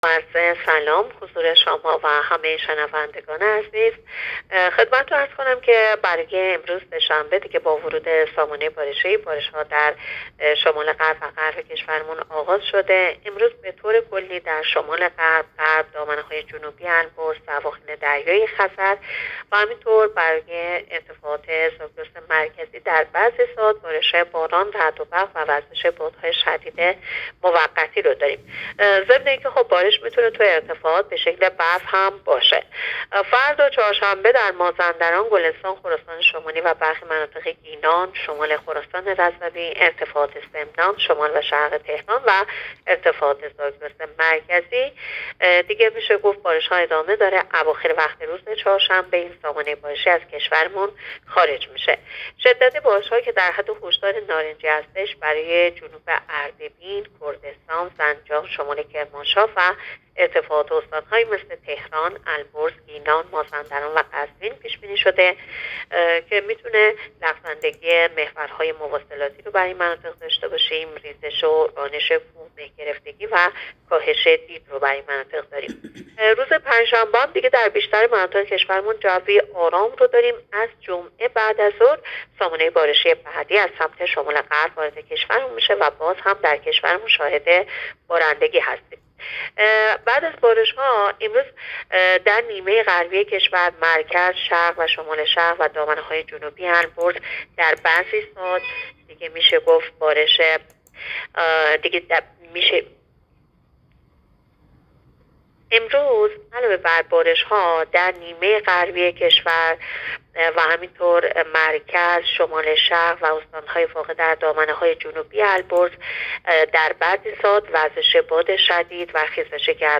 گزارش رادیو اینترنتی از آخرین وضعیت آب و هوای پنجم اسفند؛